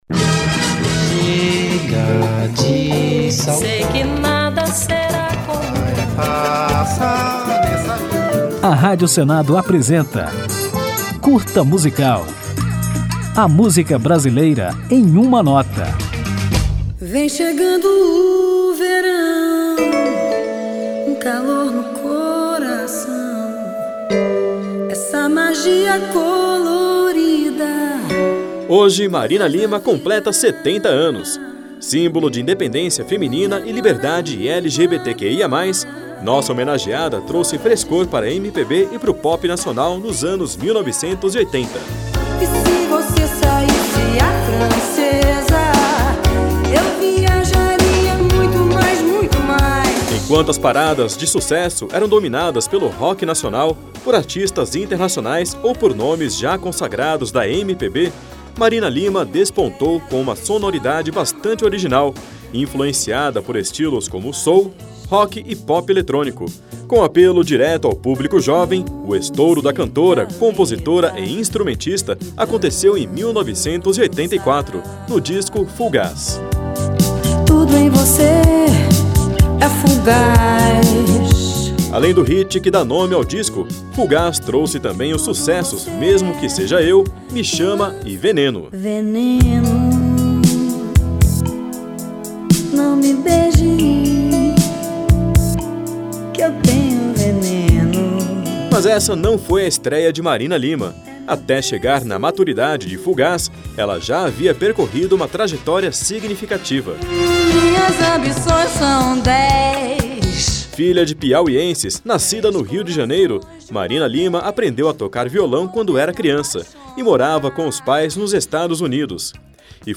Nos anos 1980, Marina Lima trouxe frescor para a MPB e para o Pop Nacional, por meio de uma sonoridade original, influenciada por blues, soul, rock e música eletrônica, além de letras que falavam diretamente ao público jovem da época. Nesta homenagem feita para os 70 anos da artista, comemorados hoje, você confere a história da cantora e compositora e ainda ouve a música Fullgás, que projetou Marina em 1984.